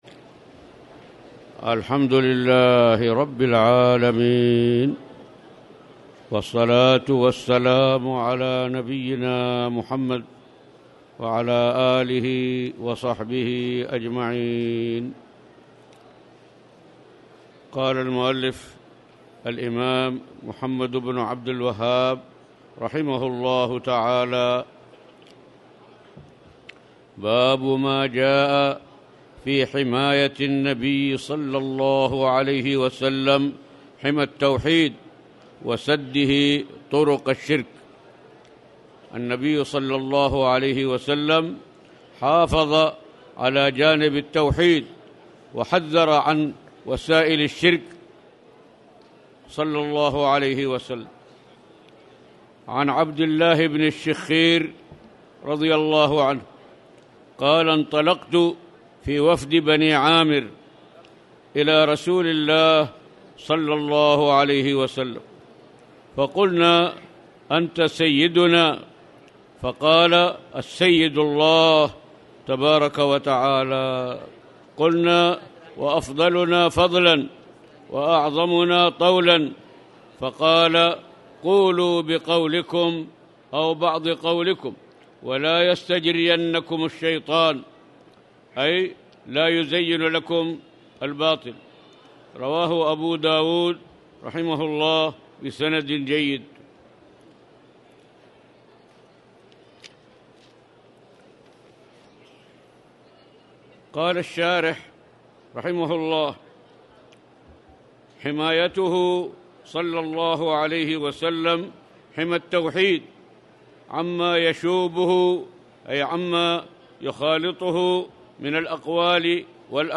تاريخ النشر ٨ شوال ١٤٣٨ هـ المكان: المسجد الحرام الشيخ